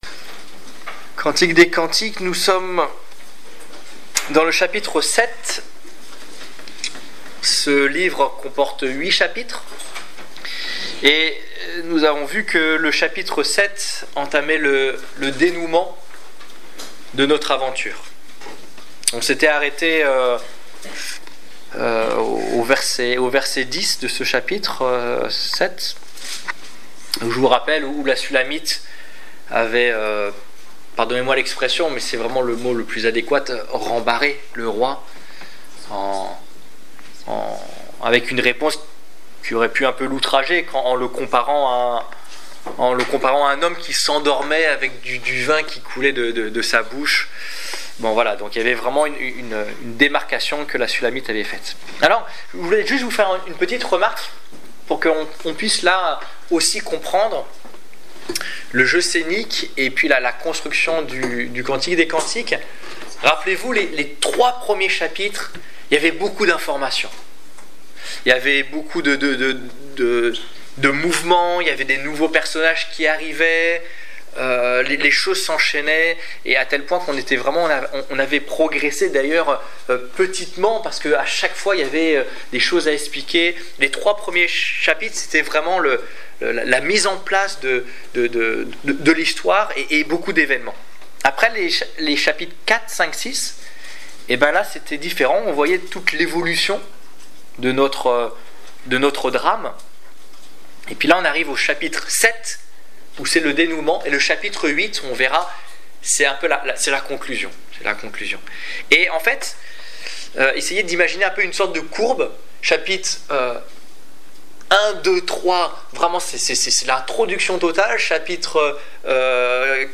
Étude biblique du 30 septembre 2015